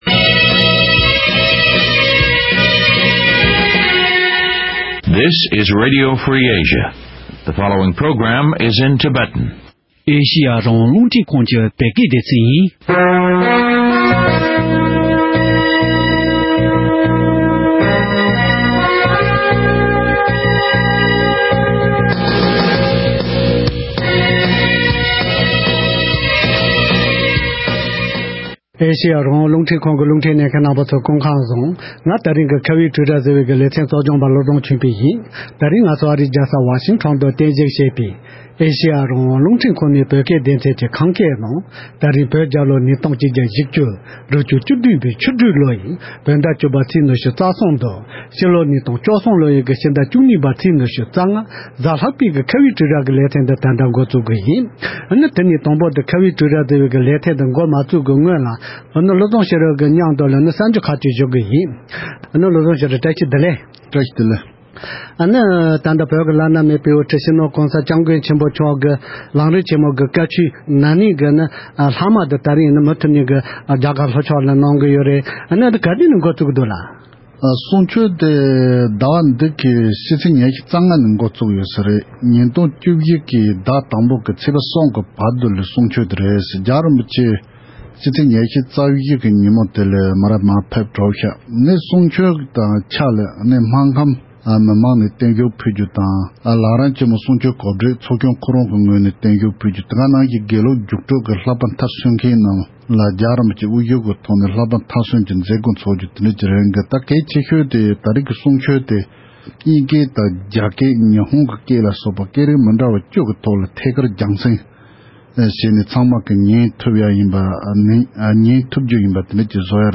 ༄༅། །ད་རེས་ང་ཚོའི་ཁ་བའི་གྲོས་རྭ་ཞེས་པའི་ལེ་ཚན་ནང་། ད་ལྟའི་དུས་སྐབས་འདི་ལ་བོད་ཕྱི་ནང་གཉིས་སུ་ཡོད་པའི་བོད་མི་གཞོན་སྐྱེས་རྣམས་ཀྱི་བརྒྱུད་ལ་ཆབ་སྲིད་ཀྱི་གོ་རྟོགས་གོང་སྤེལ་དང་། བོད་རྩ་དོན་ཆེད་དོ་དབྱིངས་བསྐྲུན་ཐབས་སུ་ཐབས་ལམ་ཇི་འདྲ་ཡོད་མེད་སོགས་ཀྱི་སྐོིར་འབྲེལ་ཡོད་མི་སྣར་གནས་འདྲི་ཞུས་ནས་ཕྱོགས་སྒྲིག་ཞུས་པའི་ལེ་ཚན་ལ་གསན་རོགས་གནང་།།